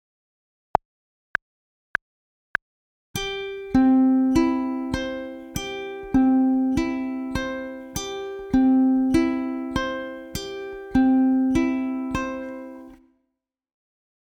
Pop/Guitar/Folk Ukulele